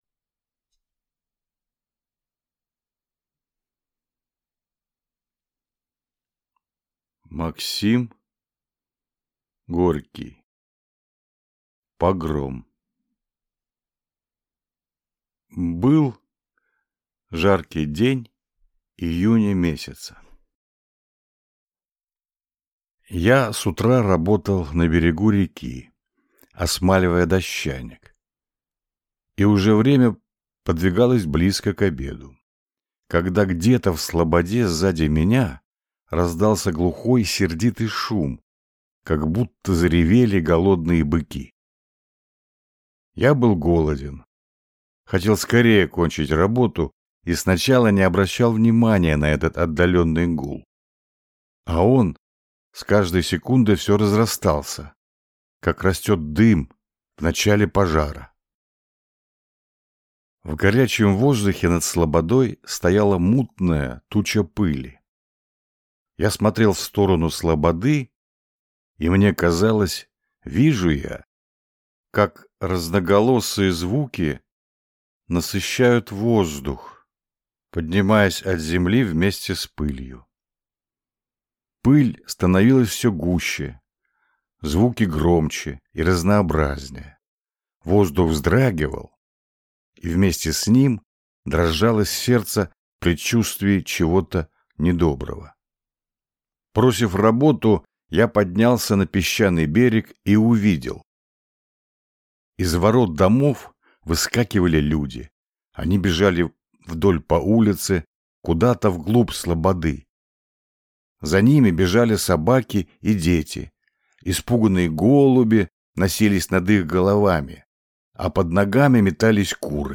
Аудиокнига Погром | Библиотека аудиокниг